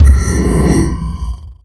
rocketpickup01.wav